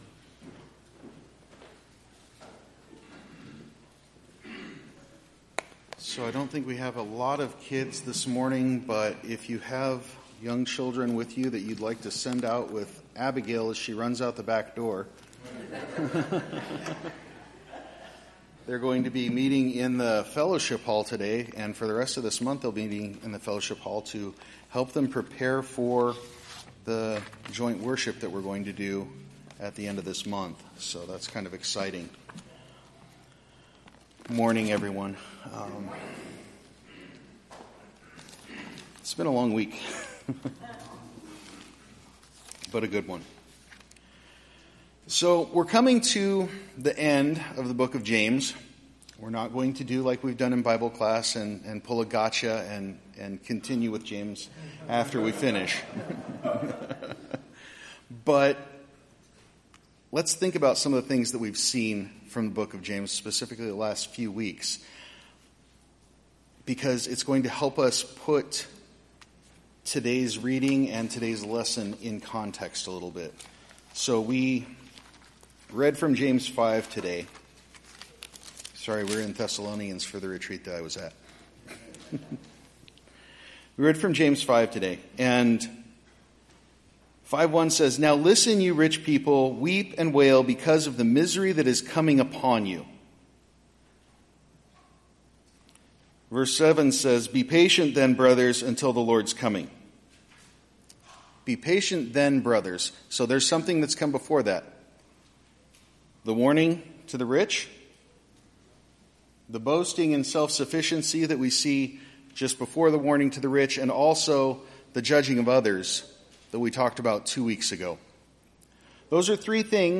Sermon-Audio-October-1-2023.mp3